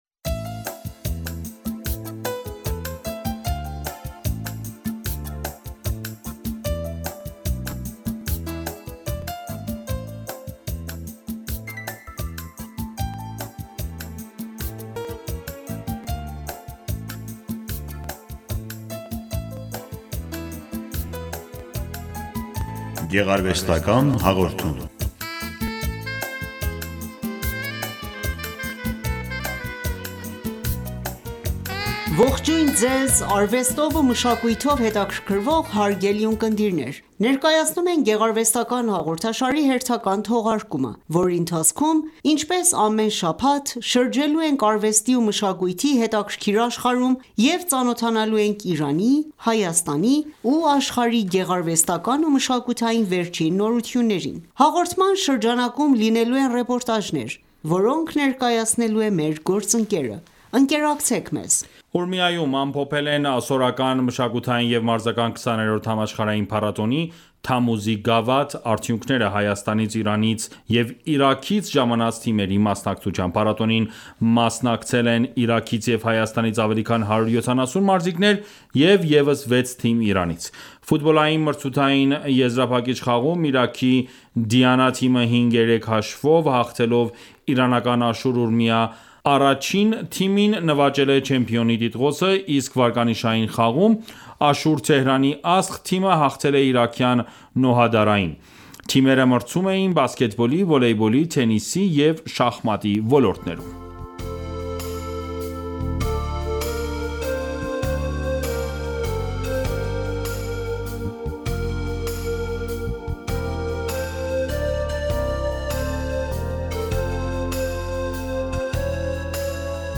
Հաղորդման շրջանակում լինելու են ռեպորտաժներ,որոնք ներկայացնելու է մեր գործընկերը։